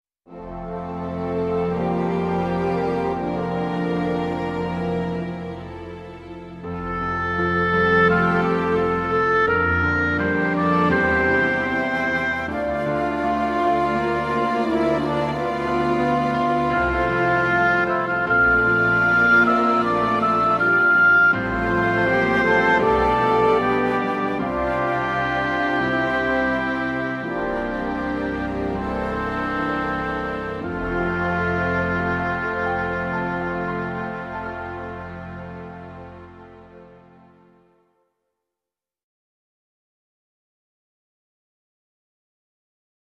Orchestral
Sound - Orchestral.mp3